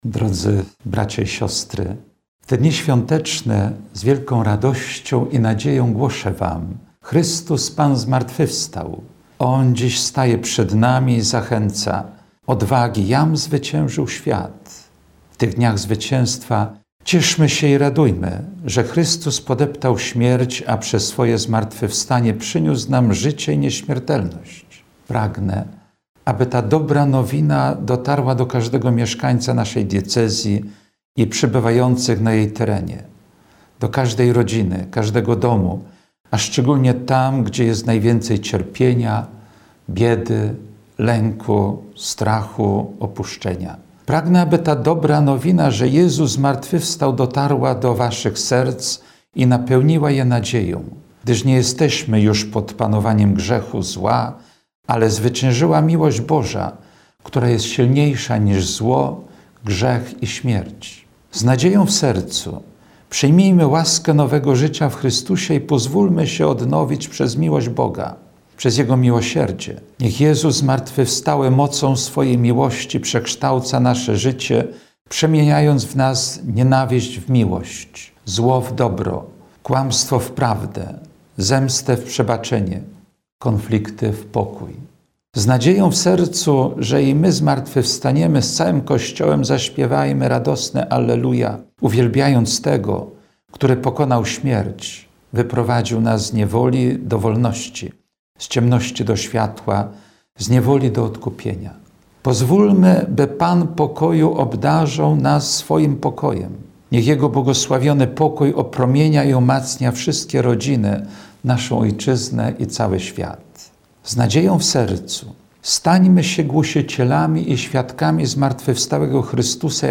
Orędzie wielkanocne Biskupa Diecezji Ełckiej
Chrystus zmartwychwstał – tymi słowami rozpoczyna orędzie wielkanocne biskup diecezji ełckiej ks. Jerzy Mazur.
oredzie-biskup-wielkanoc-2018.mp3